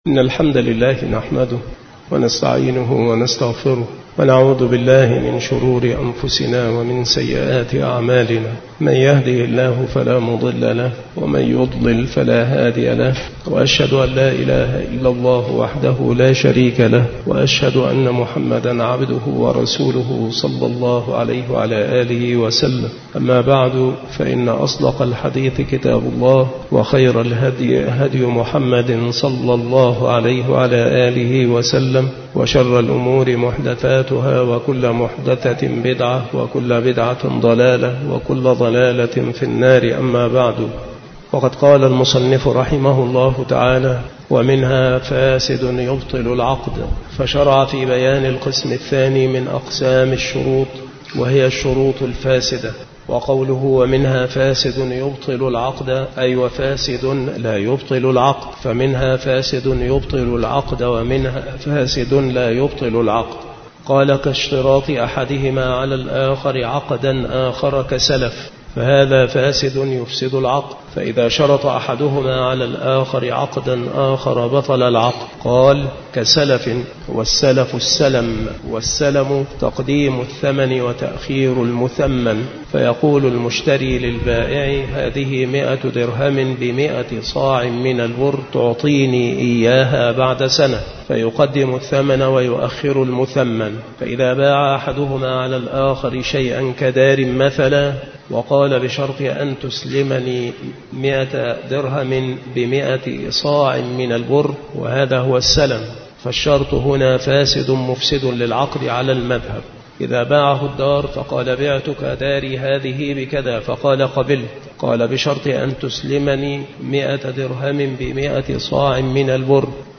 مكان إلقاء هذه المحاضرة بالمسجد الشرقي بسبك الأحد - أشمون - محافظة المنوفية - مصر عناصر المحاضرة : الفاسد الذي يبطل العقد.